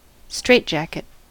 straitjacket: Wikimedia Commons US English Pronunciations
En-us-straitjacket.WAV